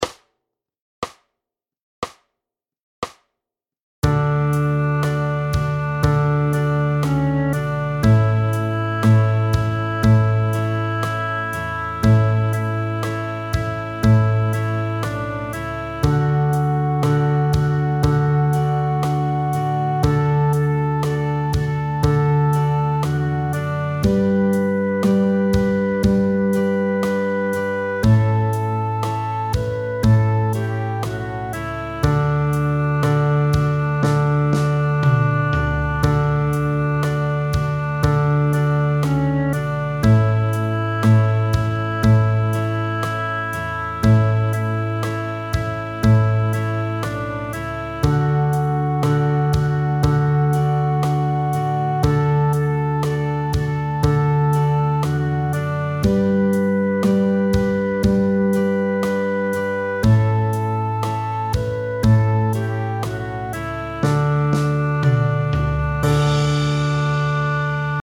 drei-chinesen-gitarre60.mp3